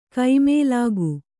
♪ kaimēlāgu